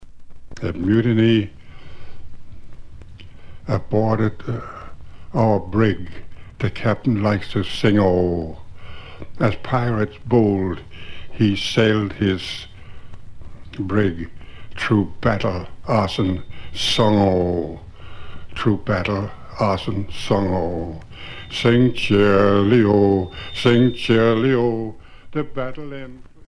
Témoignages et chansons maritimes
Témoignage